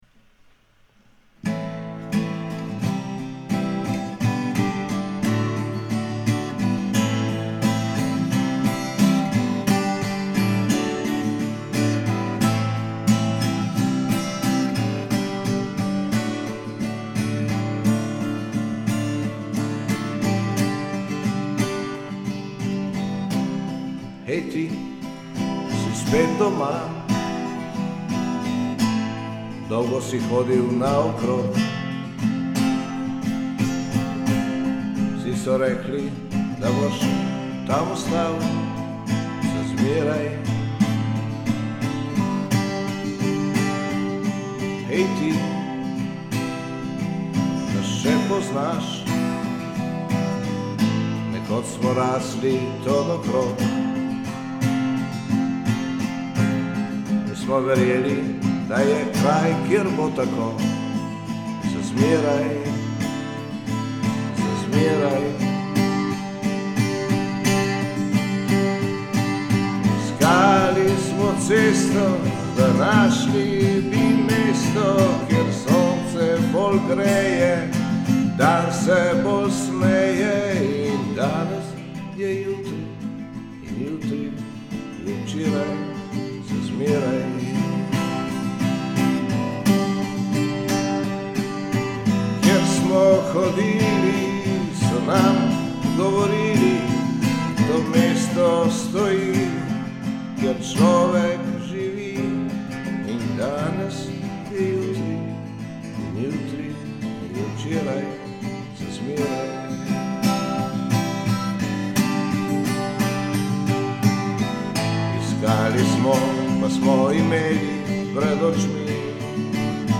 Himna